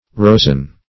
rosen - definition of rosen - synonyms, pronunciation, spelling from Free Dictionary Search Result for " rosen" : The Collaborative International Dictionary of English v.0.48: Rosen \Ros"en\, a. Consisting of roses; rosy.